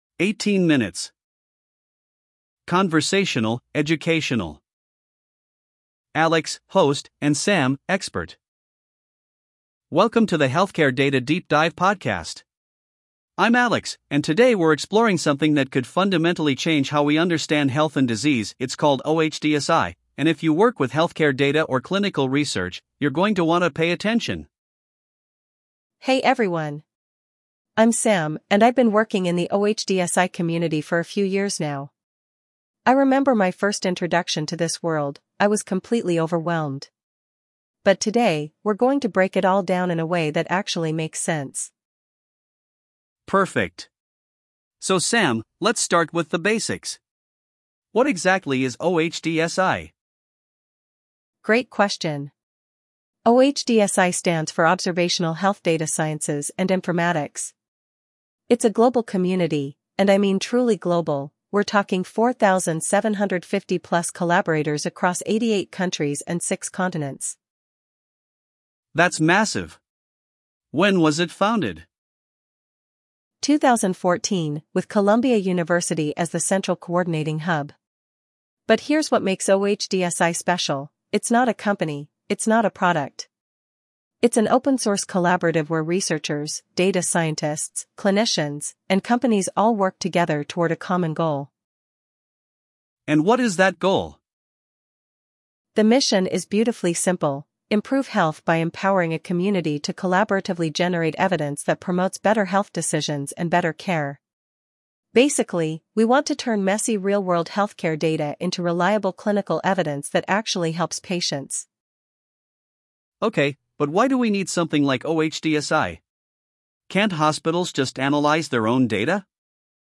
Prefer audio? Listen to this article as a conversational podcast (~18 min):